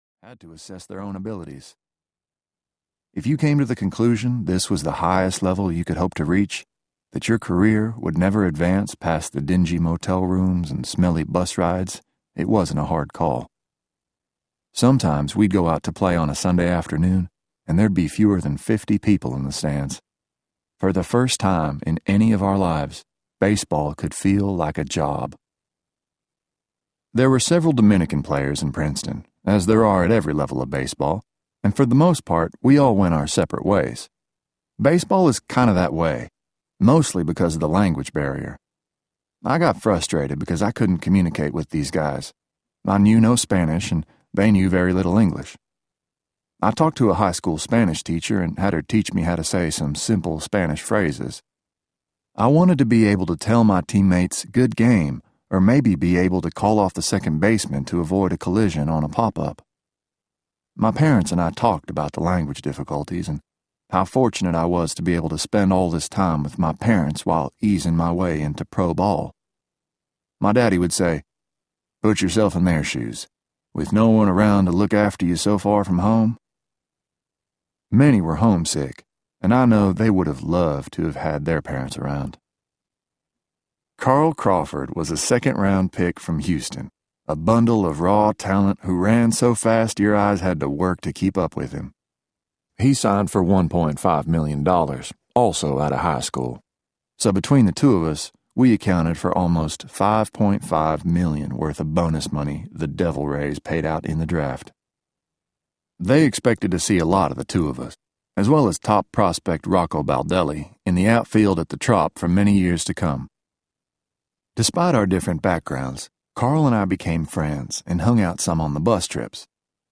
Beyond Belief Audiobook
Narrator